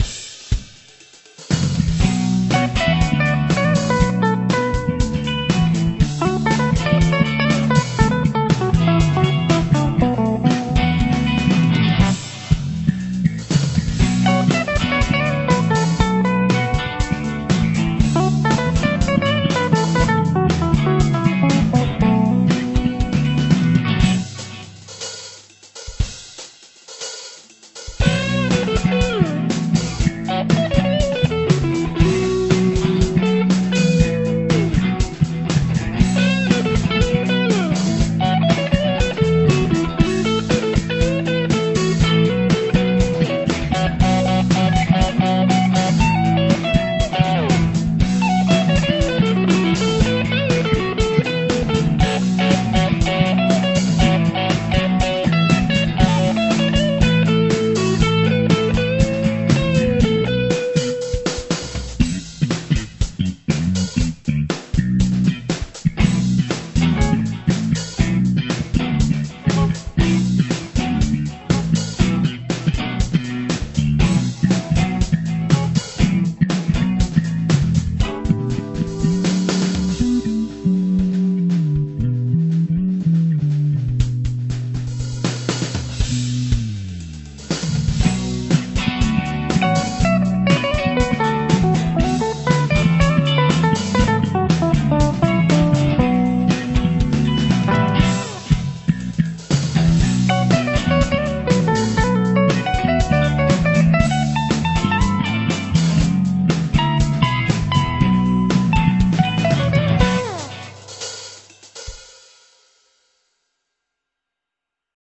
Hallo, nachdem ich mir jetzt endlich mal ein paar halbwegs vernünftige Abhör-Monitore zugelegt habe (M-Audio BX-5) habe ich mal einen meiner alten Instrumental-Tracks neu abgemischt. An ein zwei Stellen ist das nicht ganz sauber gespielt, aber mich würde interessieren was ihr zum Sound sagt und was ihr diesbezüglich vielleicht noch überarbeiten würdet. Aufgenommen wurde mit Superior Drums, der Rest ist nacheinander eingespielt und besteht aus 1x Bass, 1 x Ryth.-Guit., und 2 x Solo-Guit.